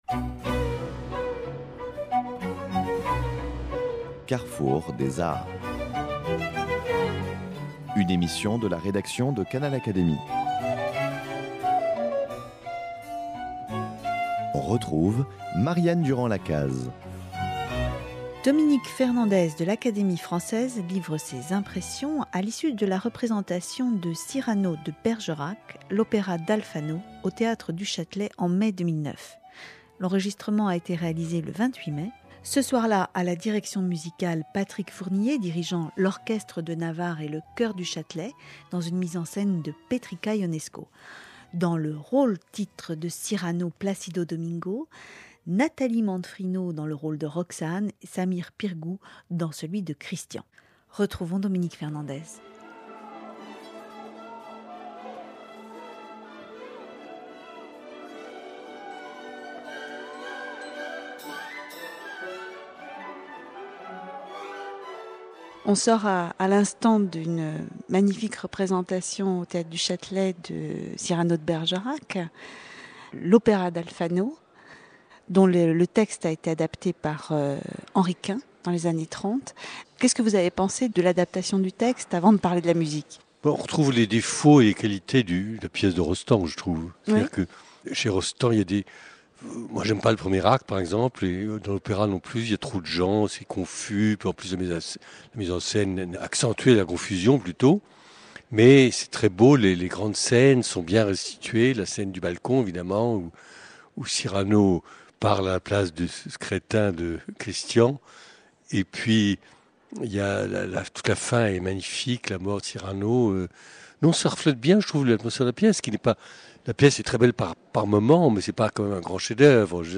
Impressions de l’écrivain et mélomane Dominique Fernandez, recueillies à la fin de la représentation de l’opéra Cyrano de Bergerac , le 28 mai 2009, au Théâtre du Châtelet. L’adaptation de la fameuse pièce d’Edmond Rostand pour l’opéra : une œuvre du compositeur italien Franco Alfano de 1935, jouée à Paris l’année suivante, puis tombée dans l’oubli jusqu’au début XXI e siècle.